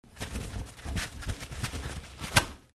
Шуршание разворачиваемой бумаги в туалете